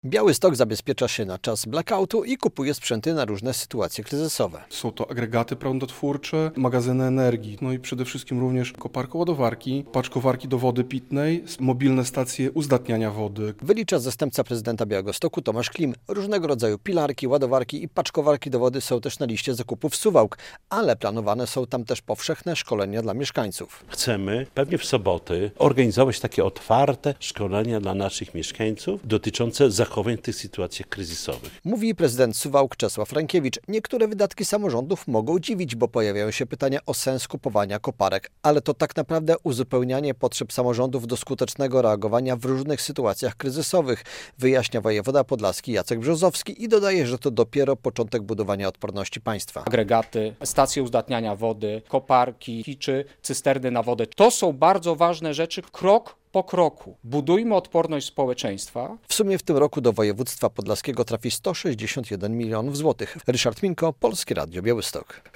Pieniądze na obronę cywilną - relacja